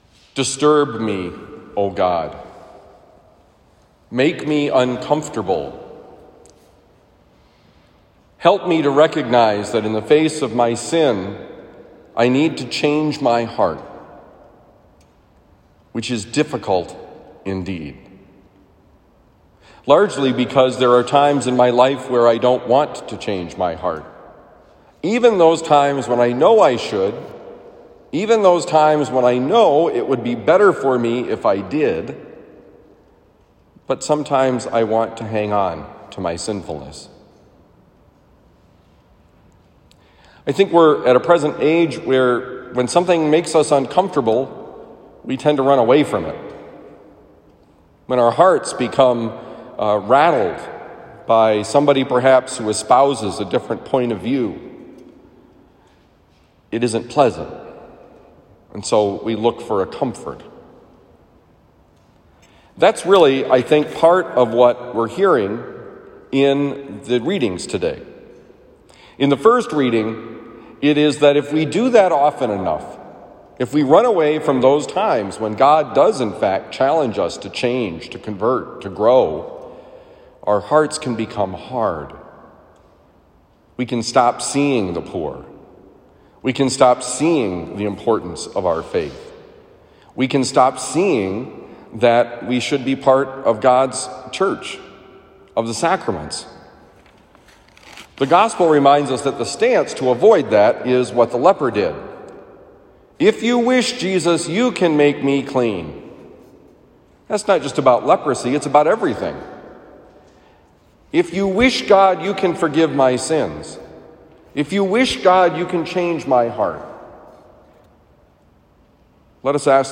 Homily for January 14, 2021
Given at Christian Brothers College High School, Town and Country, Missouri.